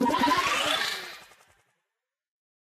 teleport.ogg